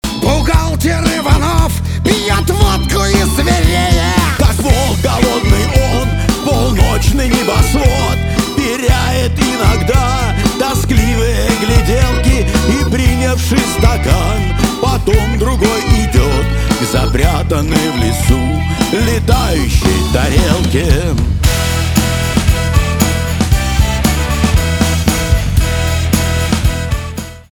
русский рок , гитара , барабаны